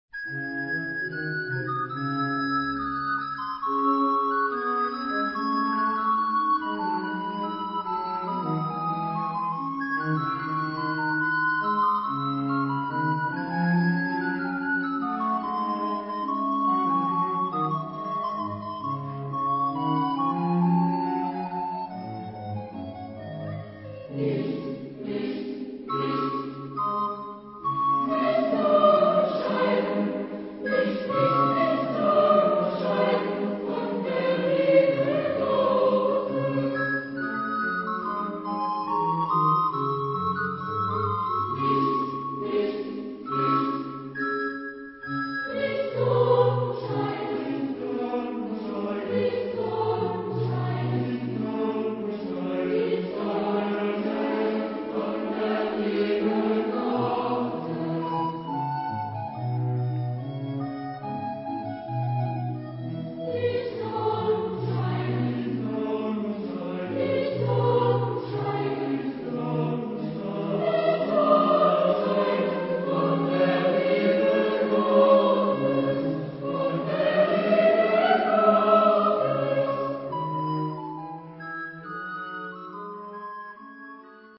Epoque: 17th century
Genre-Style-Form: Sacred ; Baroque
Type of Choir: SAB  (3 mixed voices )
Instruments: Viola (1)
Tonality: D minor